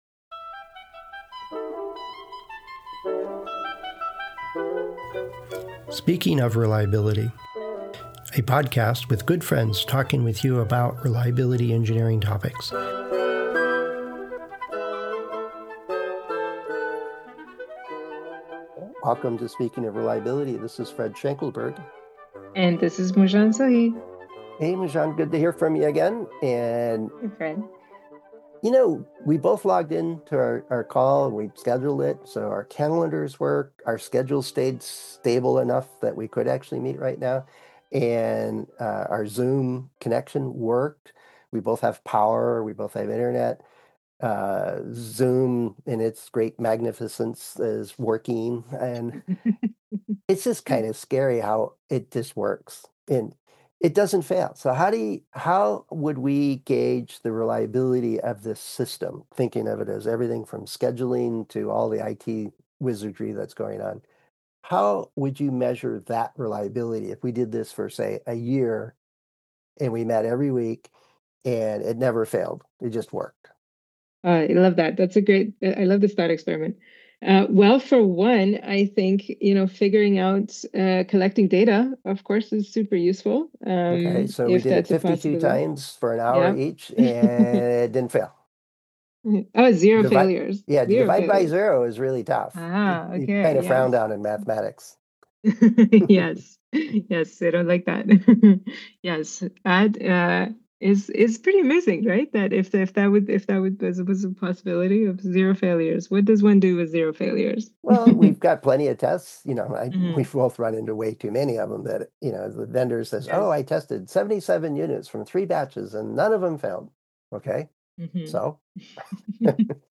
Friends Discussing Reliability Engineering Topics